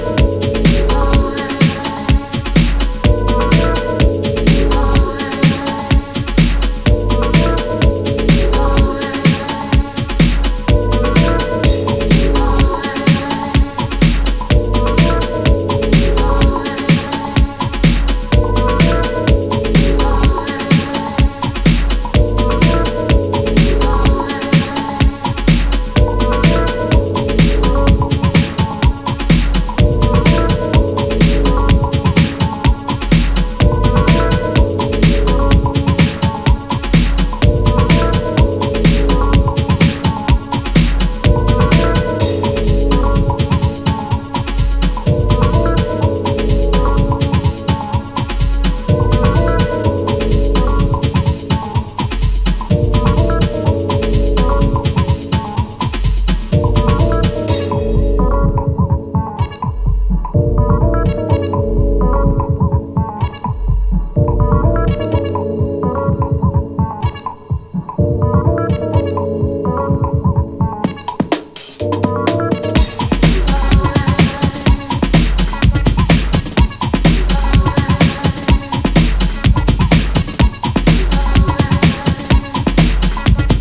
it's an EP which displays a canny variety of House music.